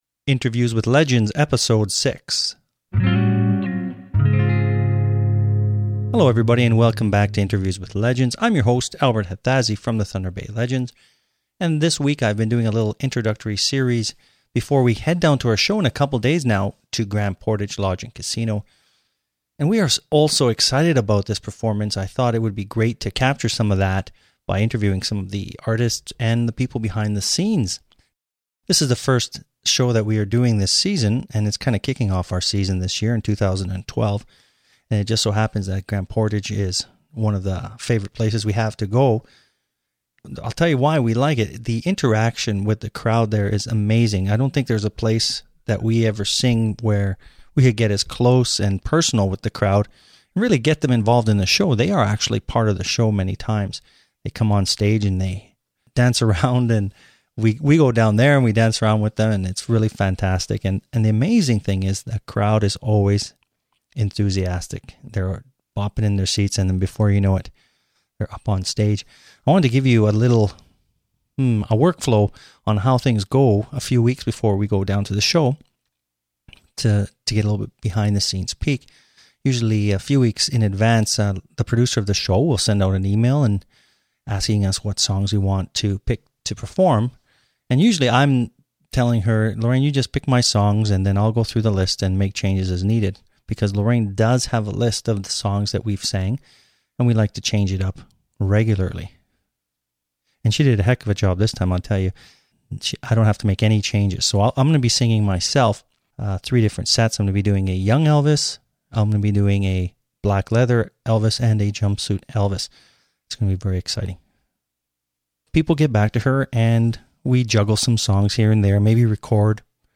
006-Thunder Bay Legend Interviews May 2012